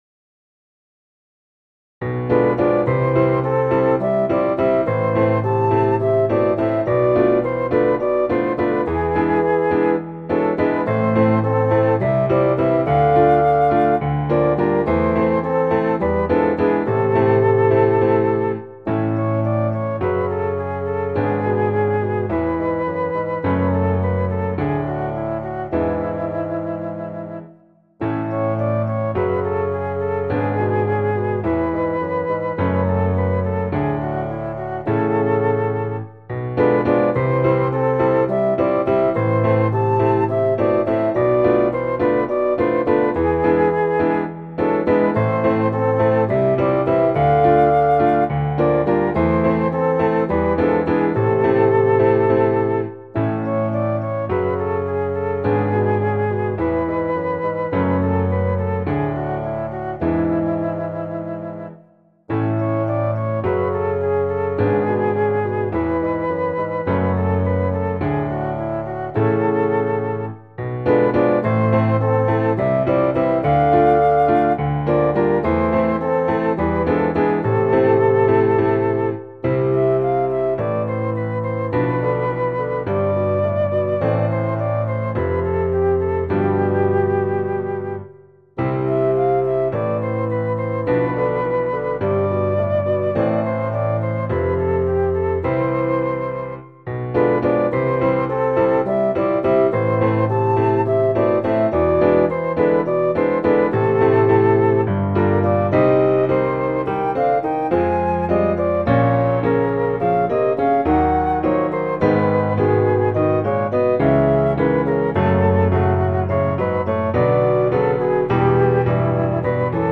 この曲の主題は、地震や放射性物質の飛散があっても春は変わらずにやってくるというものです。中間部でフルートに音域ぎりぎりの低音を苦しそうに吹かせているのは震災の気分的な影響なのでしょう。
• 楽器：フルート、クラリネット、ピアノ
• 主調：イ短調
• 拍子：2/4+3/8拍子、2/2拍子、2/4+3/8+2/4拍子
• 速度：四分音符＝105
• 楽式：ソナタ形式